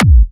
VEC3 Bassdrums Trance 07.wav